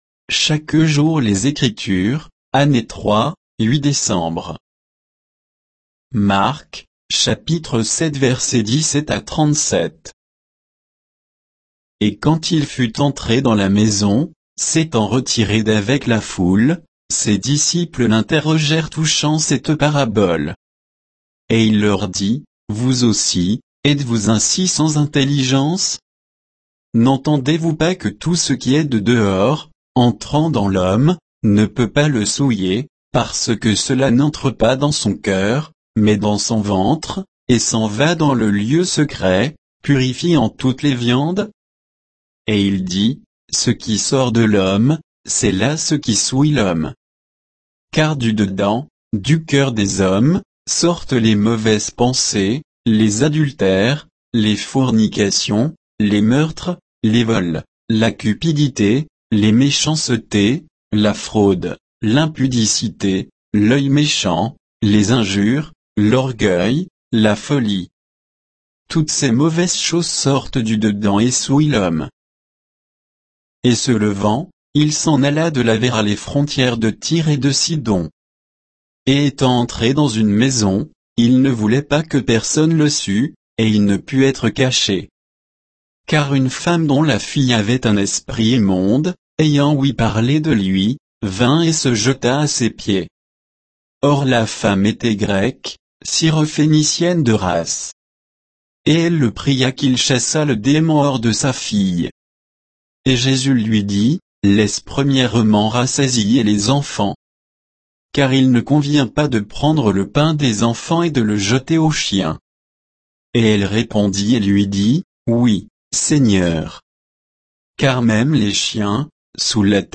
Méditation quoditienne de Chaque jour les Écritures sur Marc 7, 17 à 37